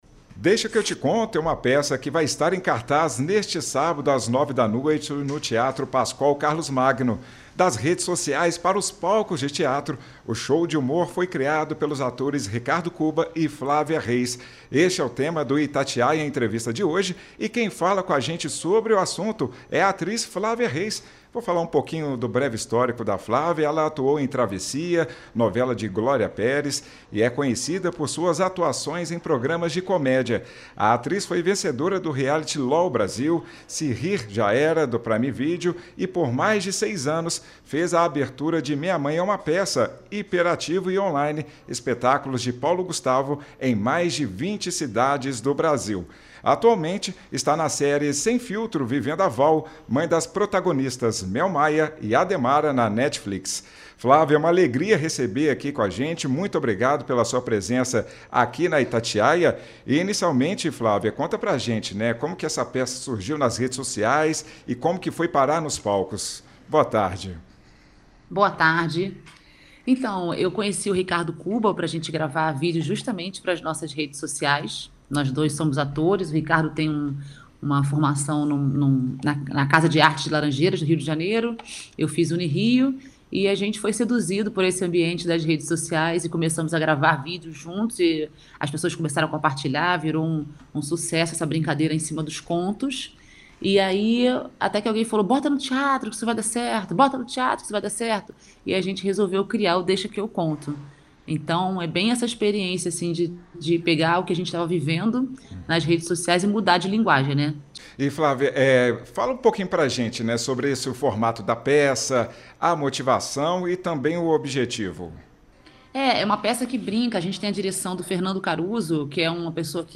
Itatiaia Entrevista